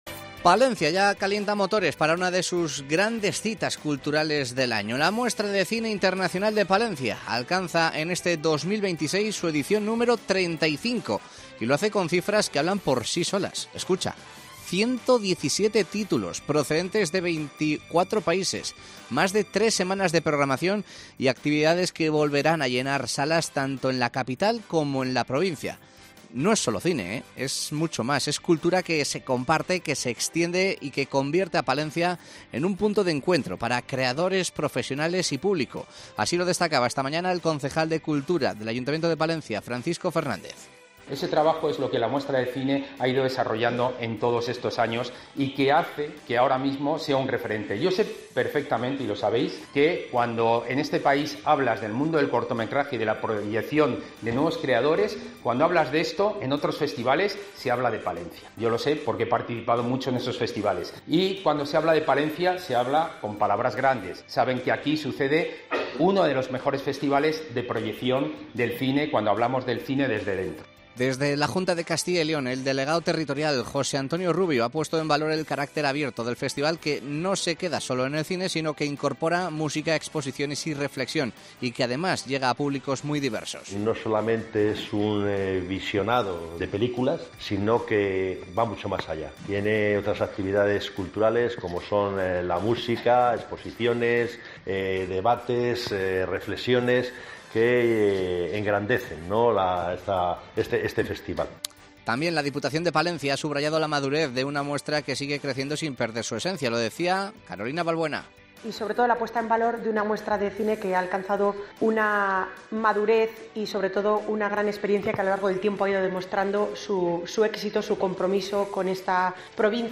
Presentación de la 35ª Muestra de Cine Internacional de Palencia